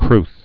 (krth)